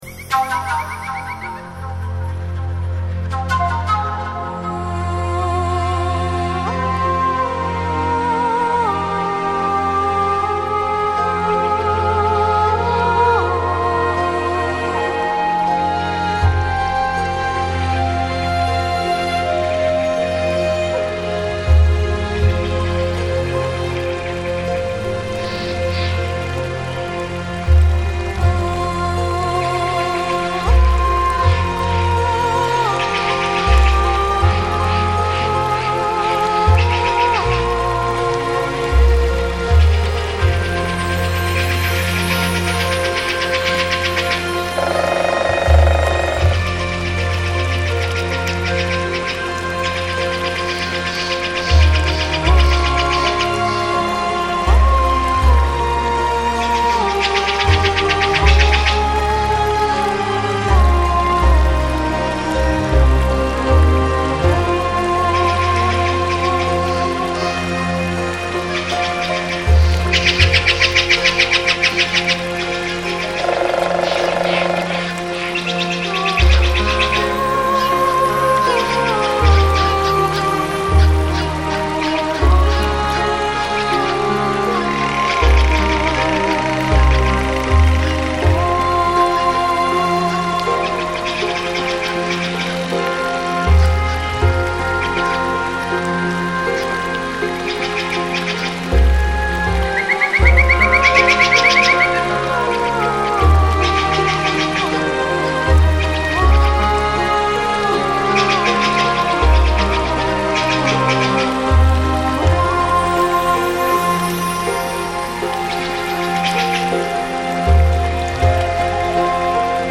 3D spatial surround sound "Spirits of the forest"
3D Spatial Sounds